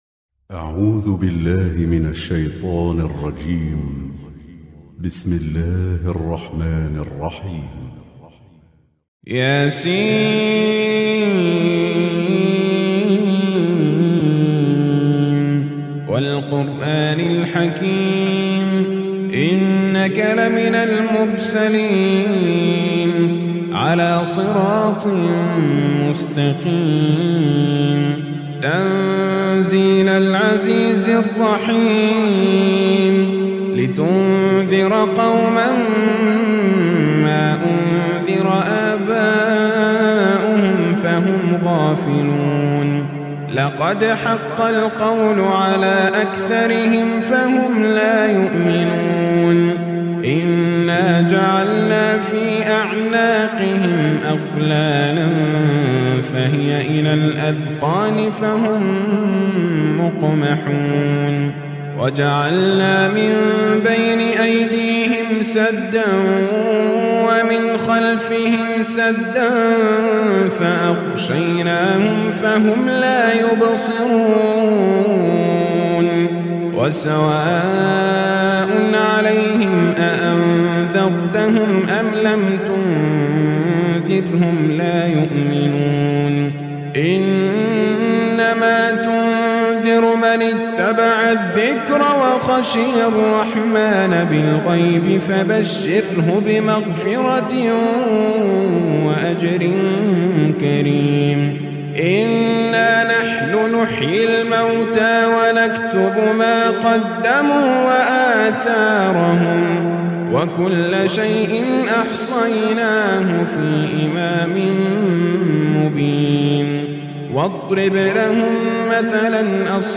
Listen Surah e Yaseen